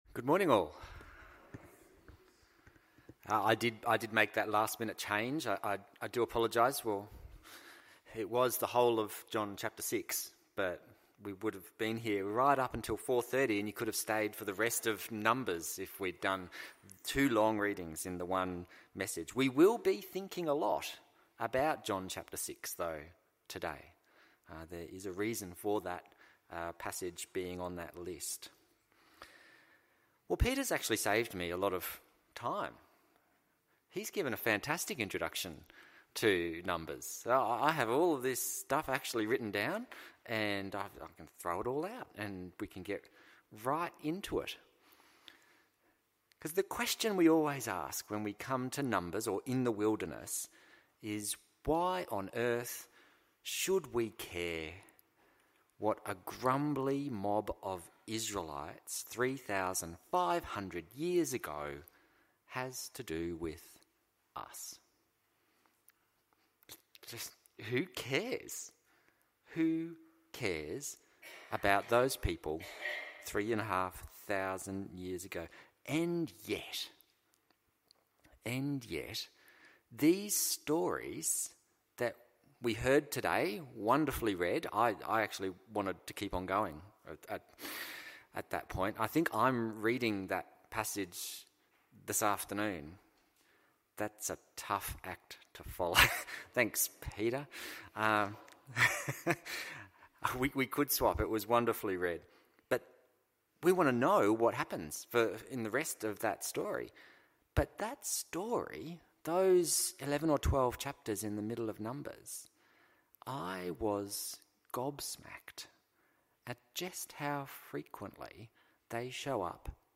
John 6:25-59 Service Type: AM Service « Do we